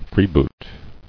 [free·boot]